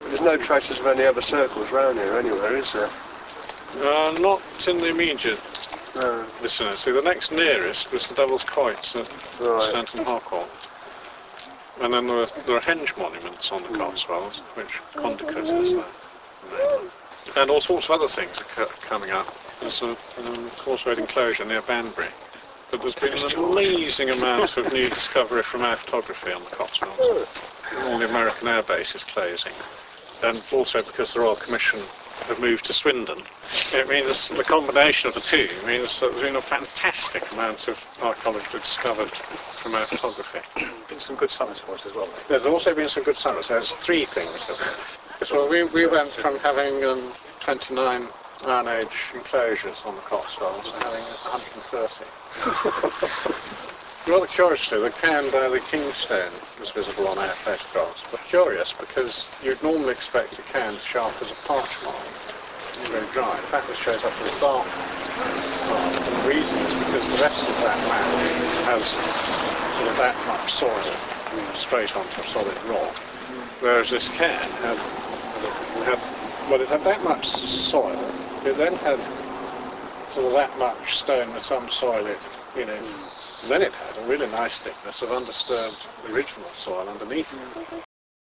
Questions & Heckling :-)
Mini-Disc recorder